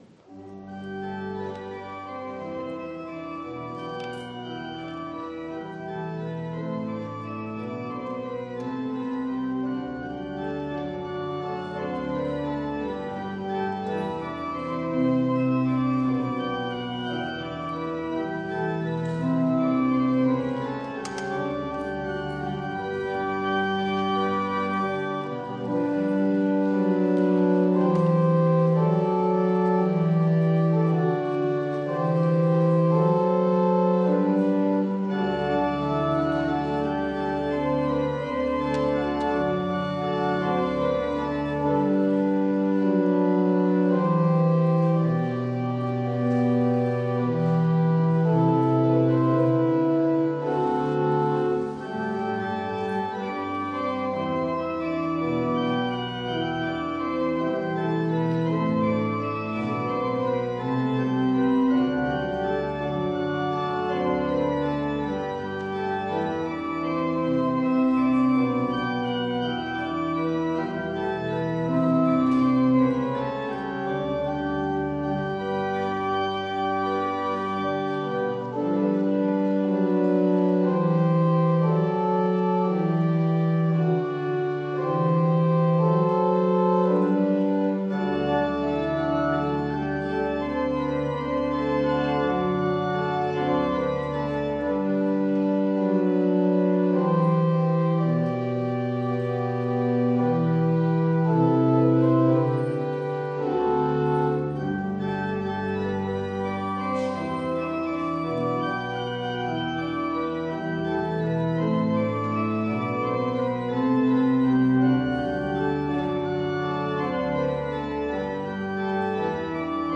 Gottesdienst am 23.01.2022
Audiomitschnitt unseres Gottesdienstes vom 3.Sonntag nach Epiphanias 2022.